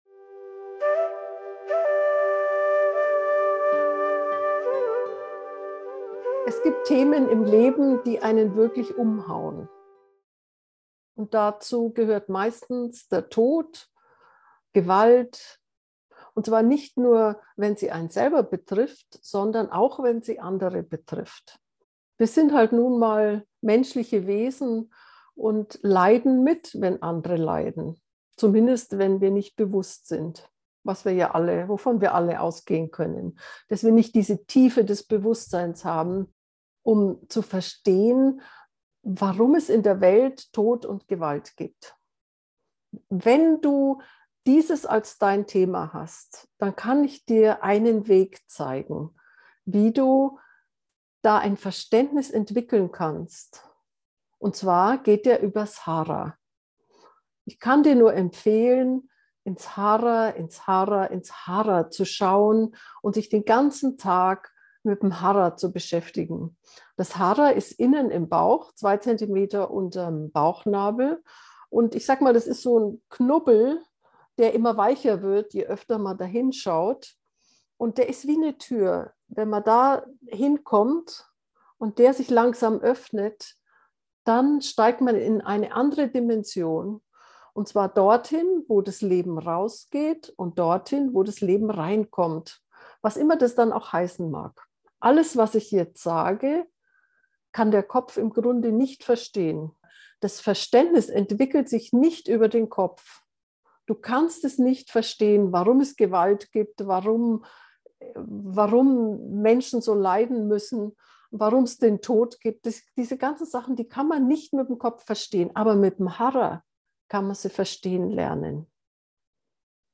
gewalt-hara-gefuehrte-meditation